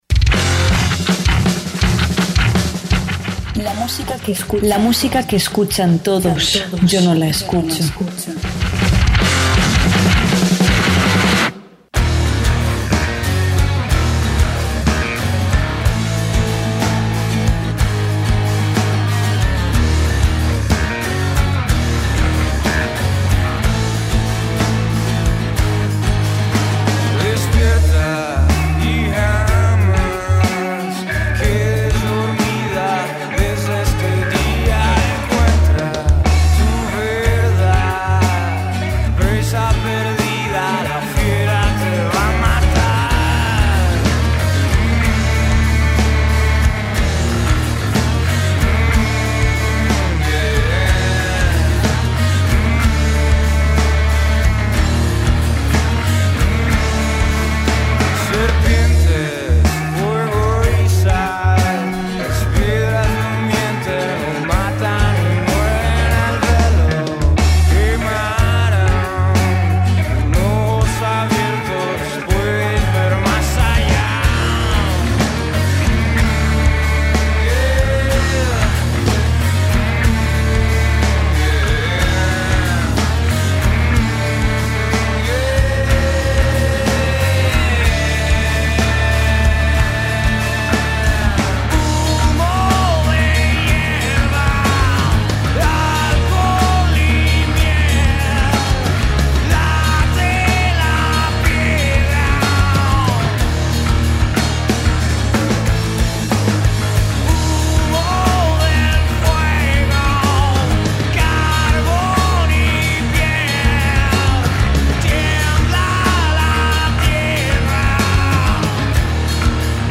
La música que escuchan todos yo no la escucho presentamos en exclusiva una entrevista